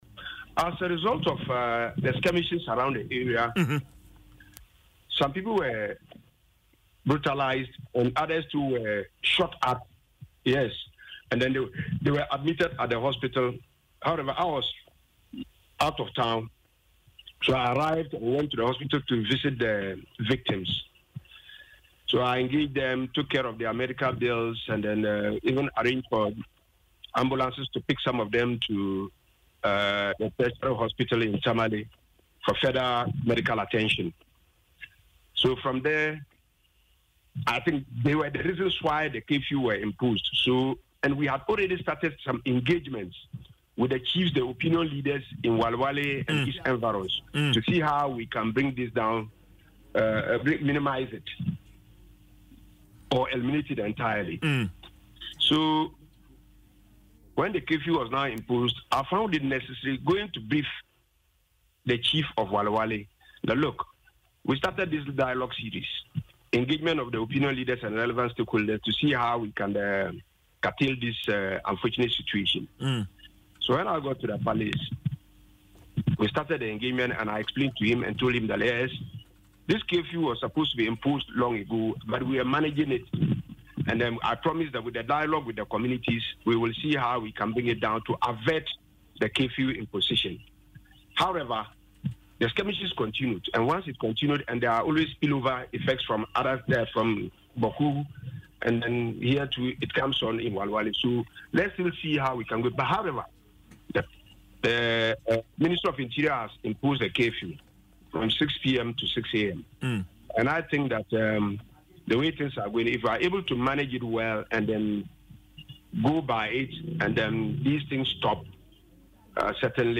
In an interview on Adom FM’s Dwaso Nsem, Mr. Ibrahim revealed he was engaging chiefs and relevant stakeholders about the curfew when the incident occurred.